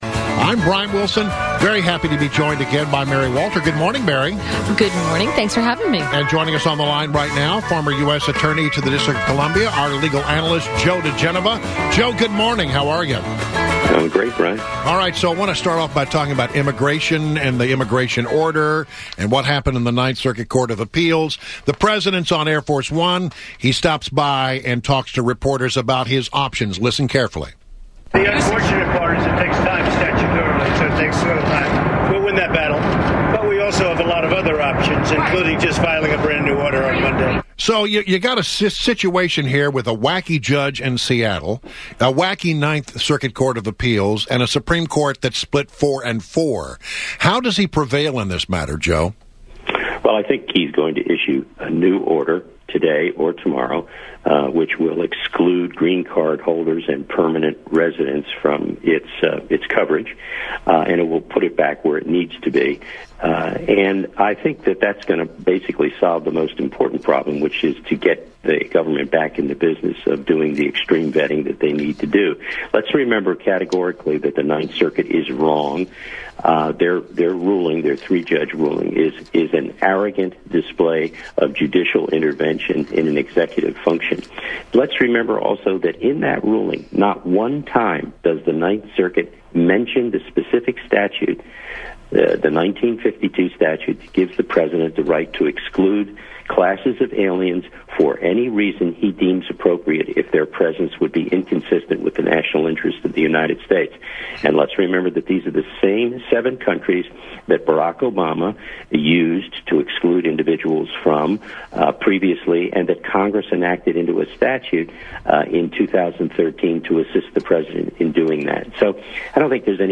WMAL Interview; Joe DiGenova 02.13.2017
Joe DiGenova, legal analyst and former U.S. Attorney to the District of Columbia.